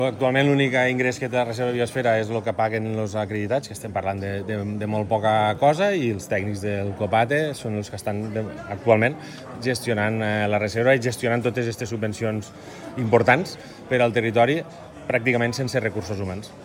Ivan Garcia, el president del COPATE